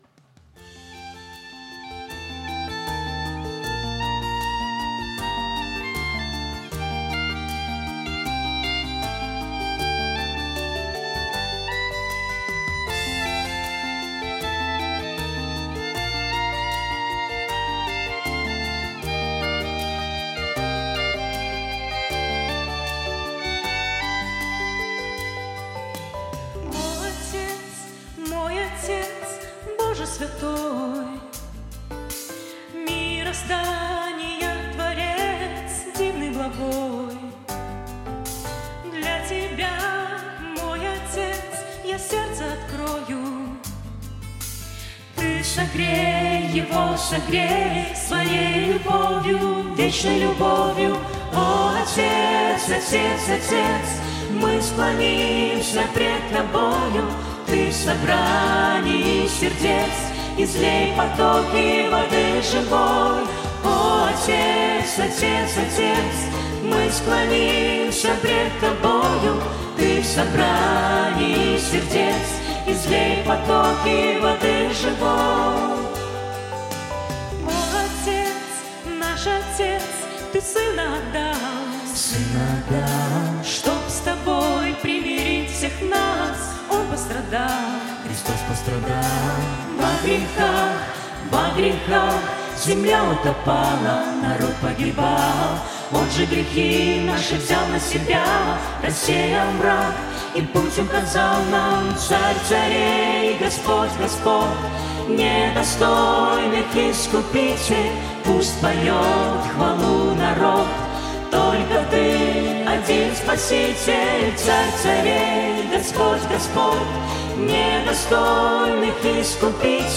Псалмы группы
С энтузиазмом и свежими силами, в новом составе с октября 2017 года группа участвует в церковных богослужениях, постоянно стремясь к развитию и регулярно обновляя репертуар.